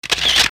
Download Camera sound effect for free.
Camera